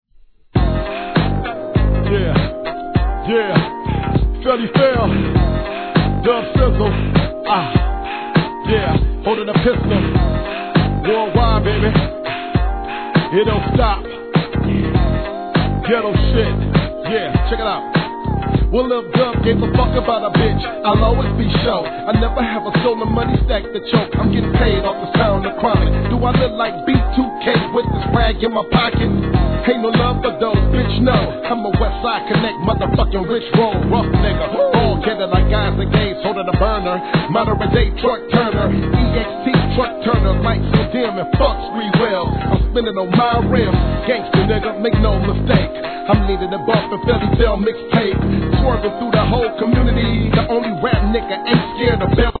G-RAP/WEST COAST/SOUTH
「メロウ&スムーズな音で、尚かつレア」